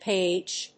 /pedʒ(米国英語), peɪdʒ(英国英語)/